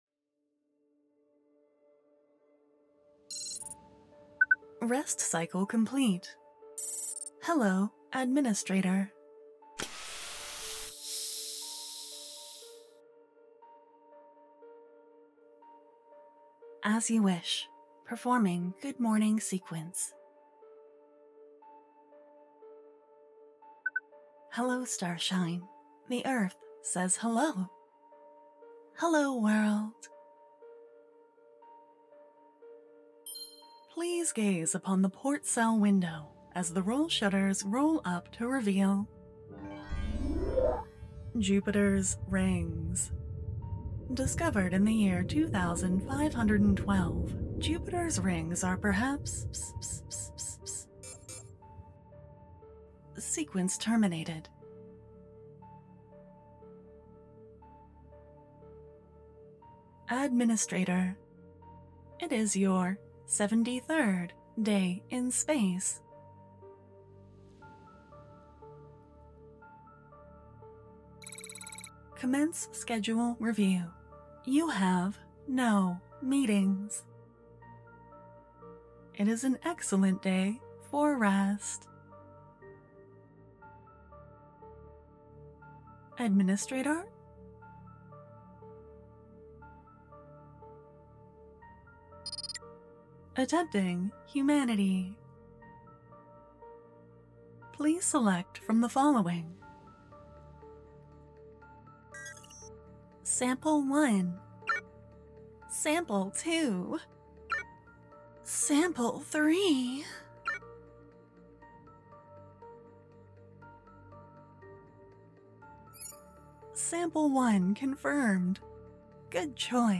Fantastic work, and the sound effects were extremely well done.